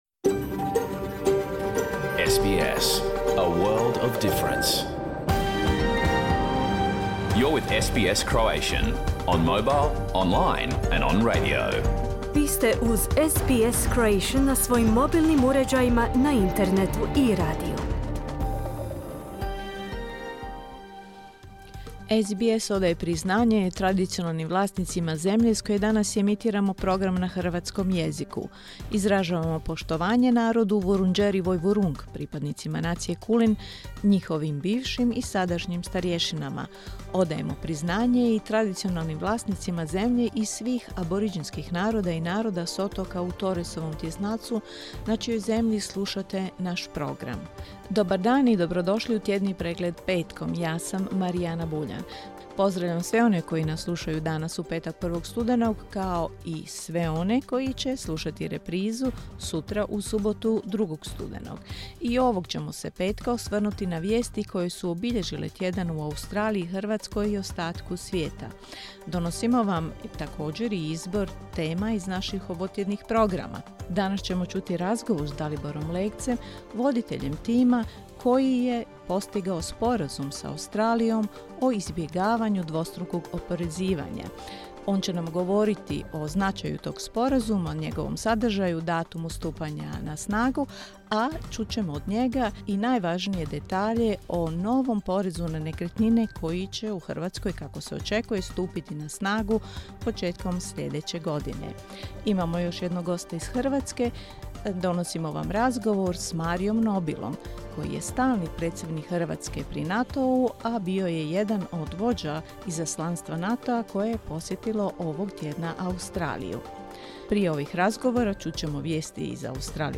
Pregled vijesti i aktualnih tema iz Australije, Hrvatske i svijeta. Emitirano uživo na radiju SBS1 u petak, 1. studenog, u 11 sati po istočnoaustralskom vremenu.